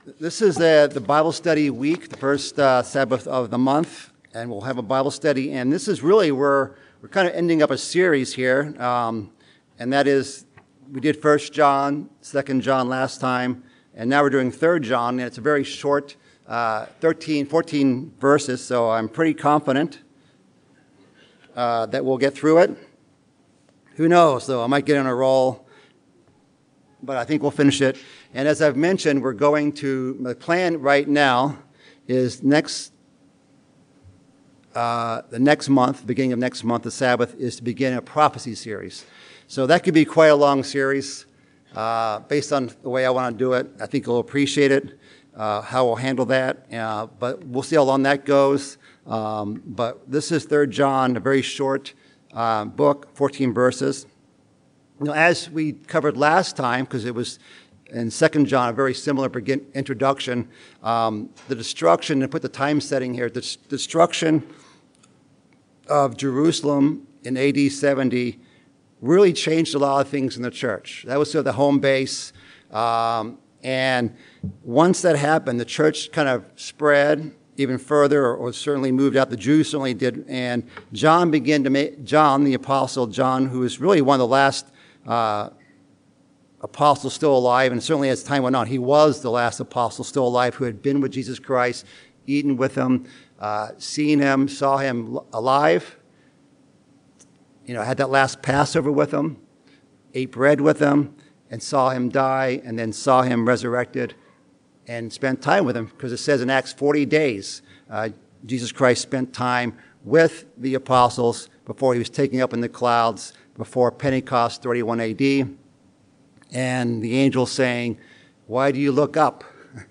Bible Study: 3 John